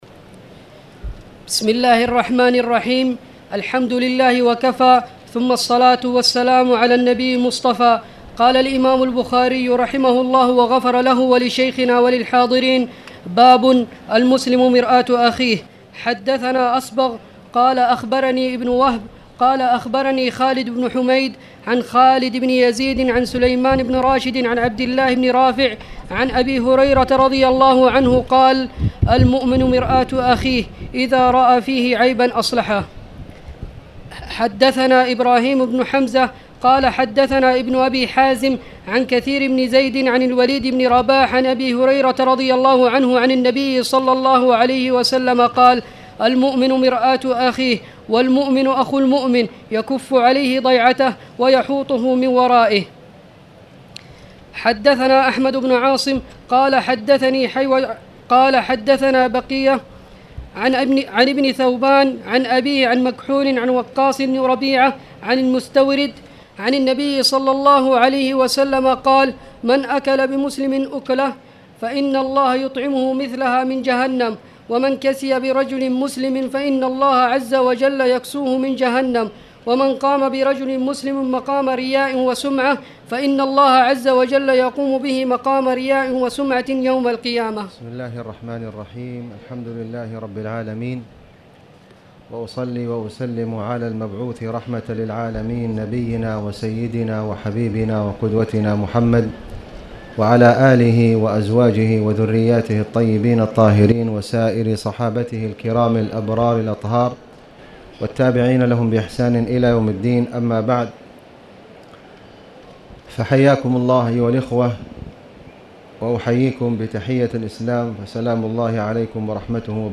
تاريخ النشر ٢١ صفر ١٤٣٨ هـ المكان: المسجد الحرام الشيخ: فضيلة الشيخ د. خالد بن علي الغامدي فضيلة الشيخ د. خالد بن علي الغامدي باب مالا يجوز من اللعب والمزاح